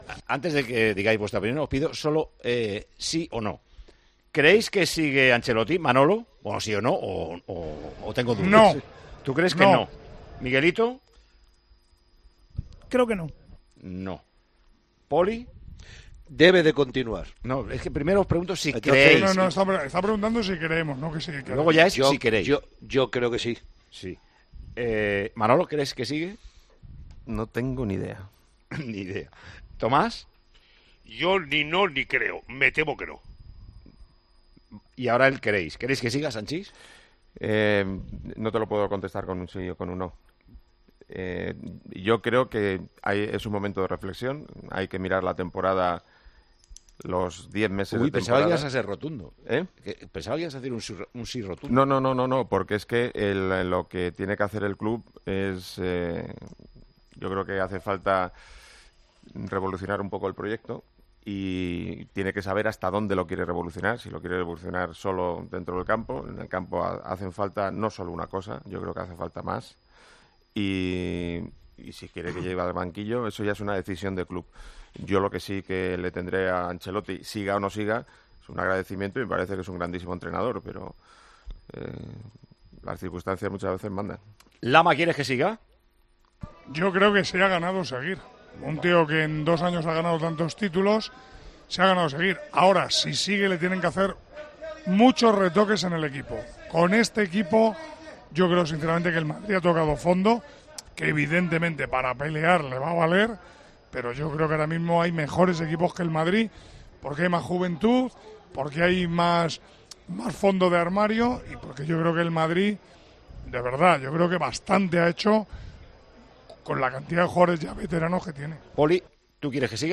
Los tertulianos de 'Tiempo de Juego' se preguntaron sobre qué pasará con el devenir de la plantilla blanca, con muchos pidiendo cambios: "El Madrid necesita una revolución".
Con Paco González, Manolo Lama y Juanma Castaño